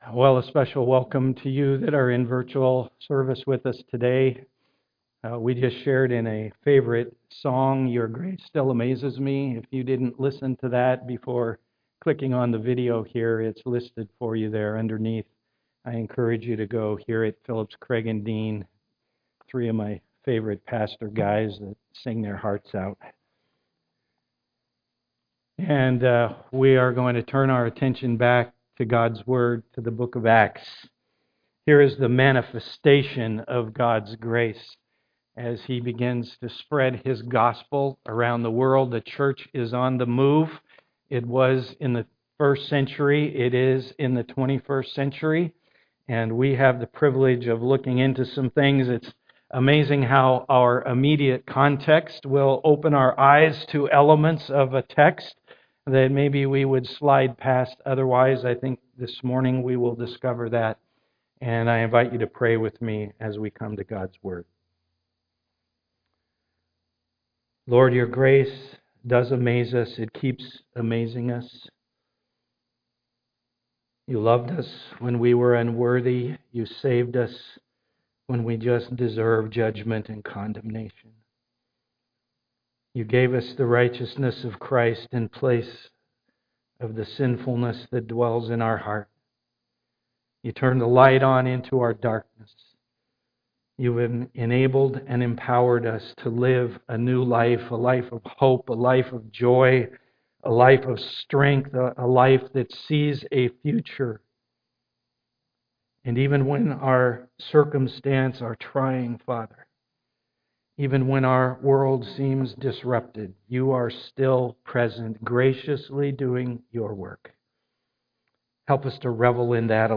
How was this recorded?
The message will be recorded live on Sunday morning and posted by early afternoon for our virtual service.